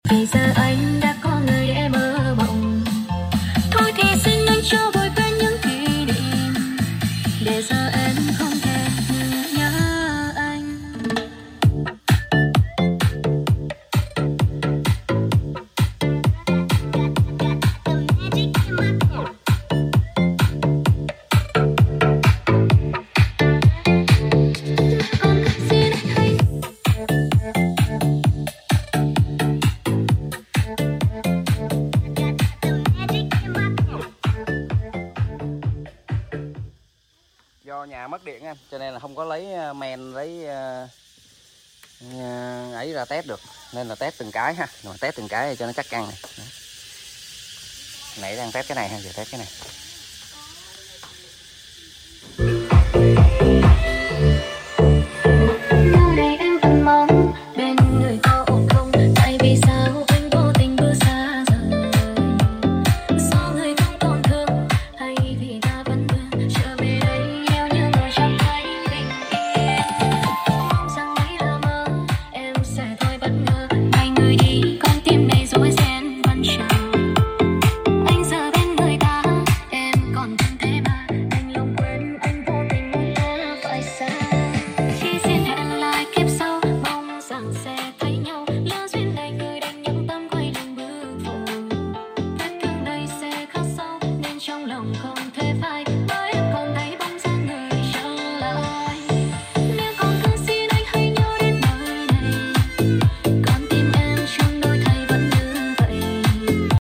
Chất âm loa Martin xịn sound effects free download
Chất âm loa Martin xịn quá hay